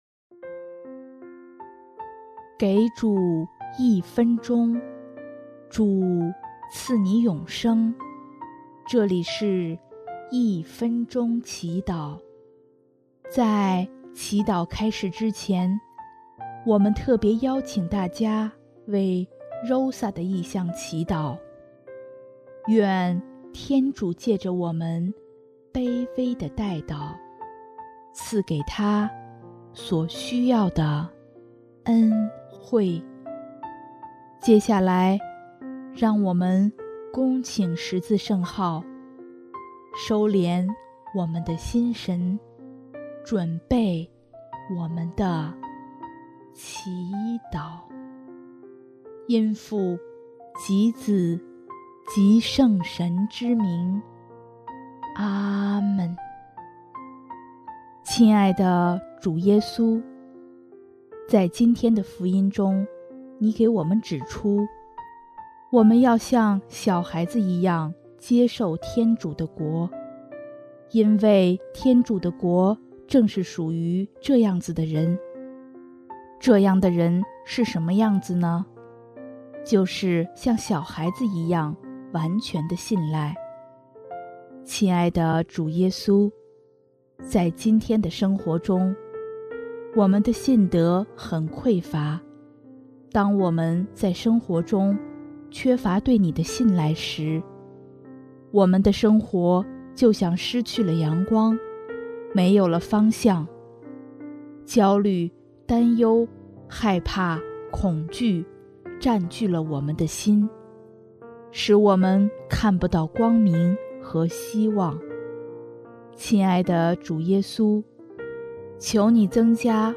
【一分钟祈祷】|5月25日 像小孩子一样信赖
音乐： 第一届华语圣歌大赛参赛歌曲《我像婴孩在你怀抱中》